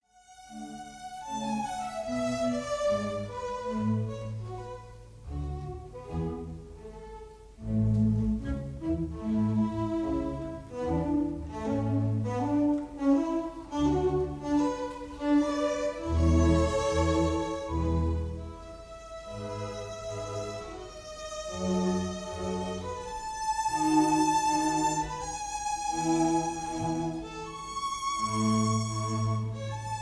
Recorderd live at the 1st Aldeburgh
Jubilee Hall, Aldeburgh, Suffolk